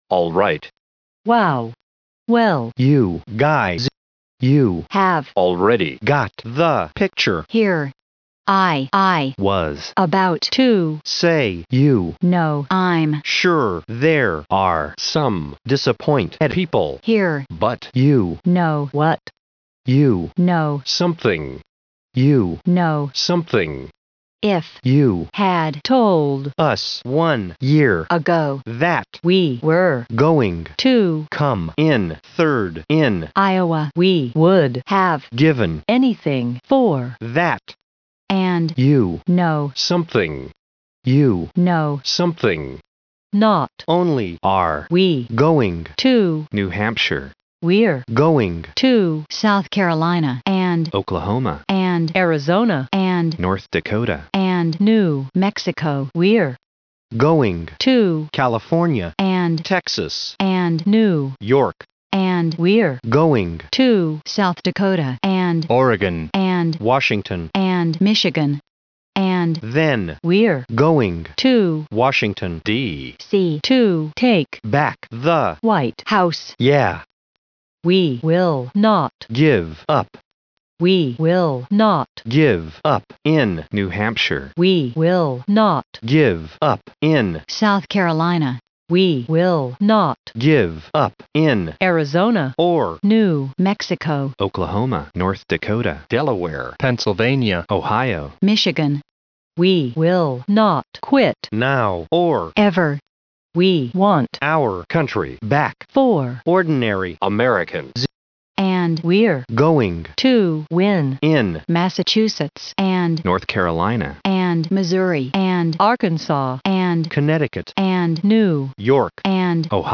Rather than splice his actual scream into some other song, I thought I'd take some inspiration from the excellent Dictionaraoke and reconstruct the speech using sound files from Merriam-Webster Online.